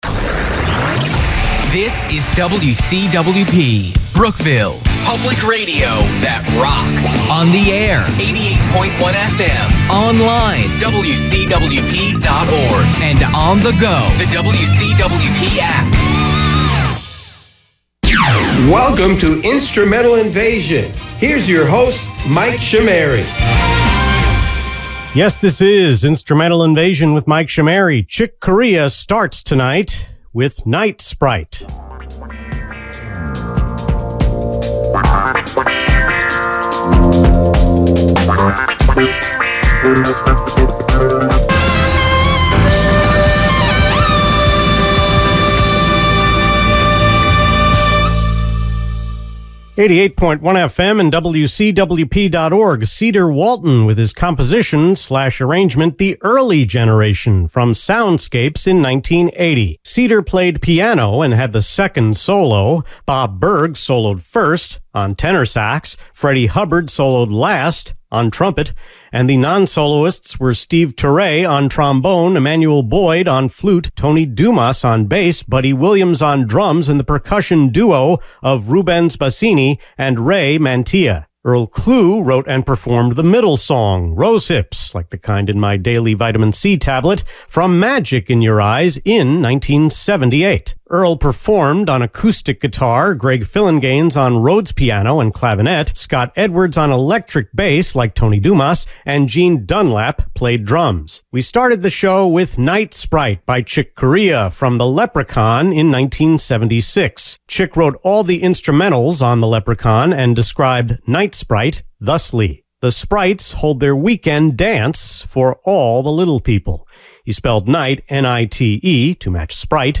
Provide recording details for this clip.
The talk break script was drafted on the 21st.